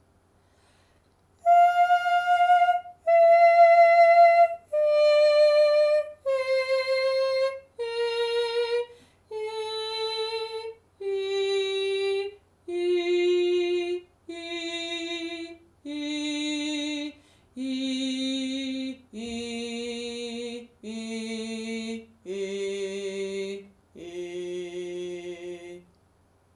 With a piano, other instrument or tuner to help you, sing a short “ee” starting in your high register.
As you head down towards your low register, you should hear the quality of your sound changing before it lands nicely in the low register, just as it does in the recording below of me singing across two breaks.
Singing across the break